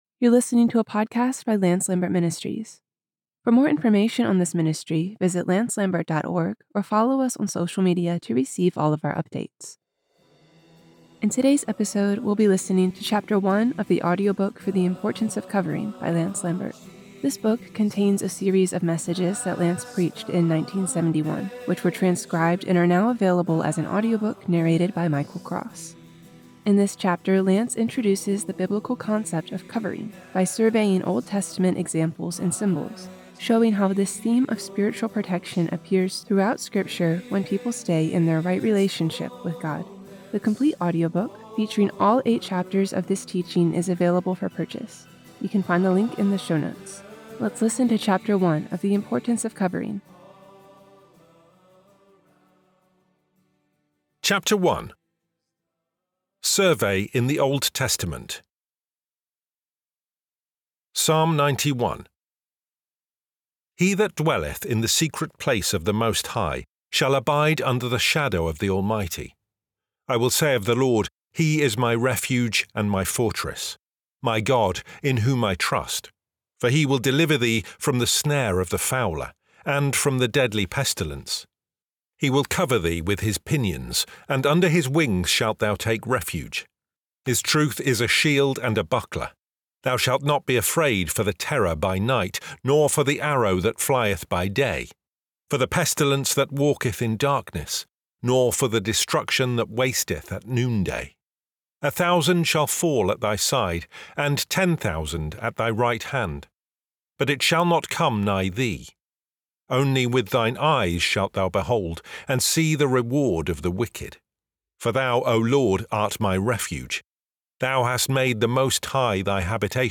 The Importance of Covering — Audiobook Chapter 1